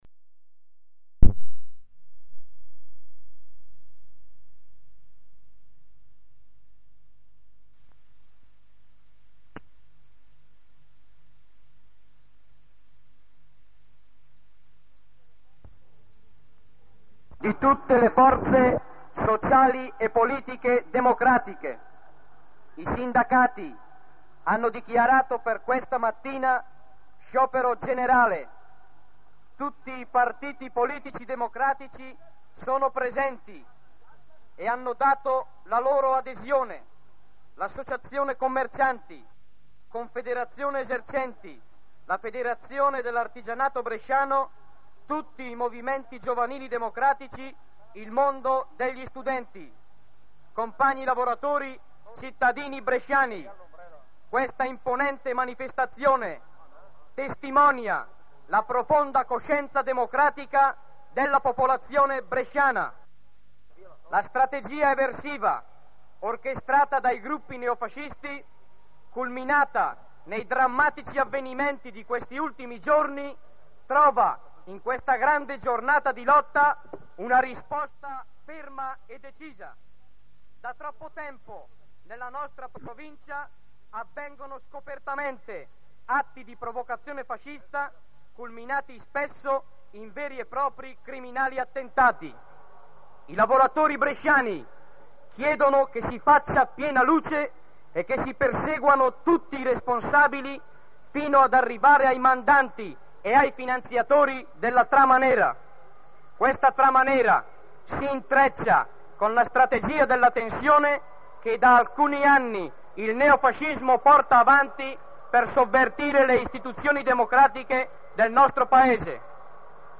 Brescia-piazza-28-maggio74.mp3